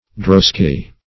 drosky \dros"ky\ (dr[o^]s"k[y^]), n.; pl. Droskies